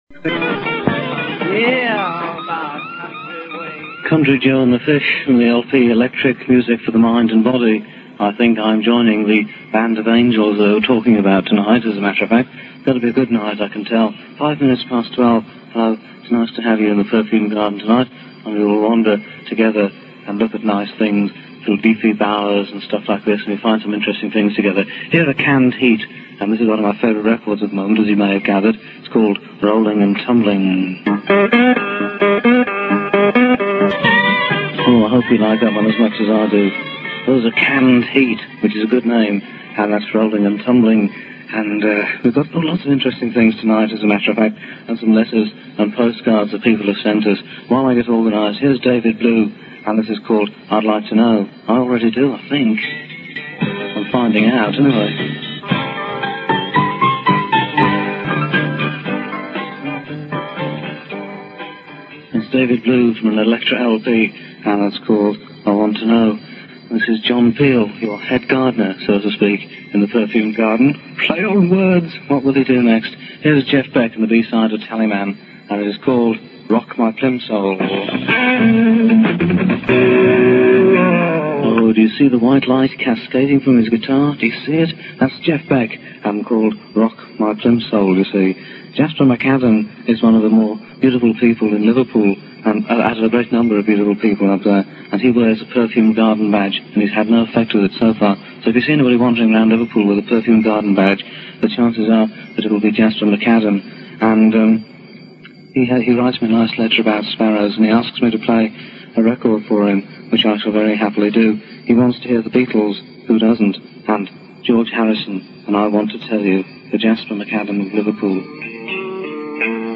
The Perfumed Garden was a unique and bizarre blend of Winnie the Pooh, San Francisco psychedelia, poetry, some of the more adventurous singles from the Big L playlist, John's thoughts on peace and love, the latest releases from obscure English hippies and the odd burst of the blues.